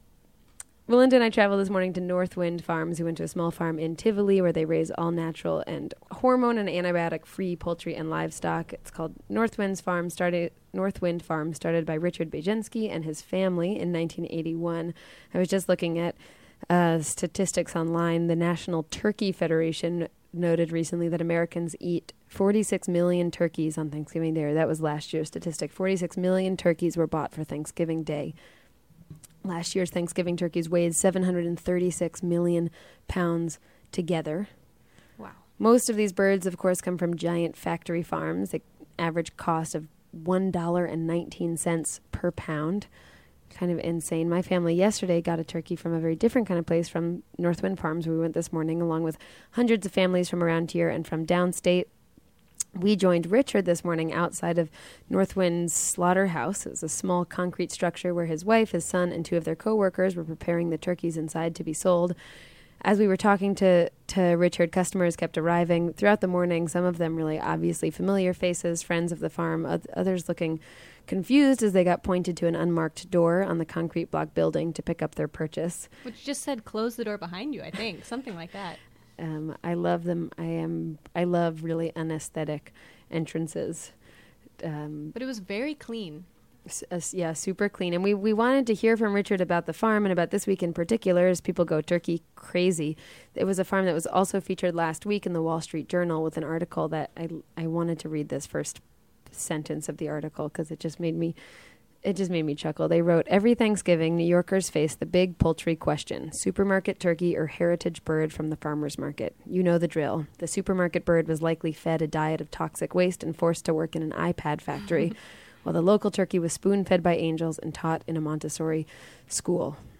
WGXC-90.7 FM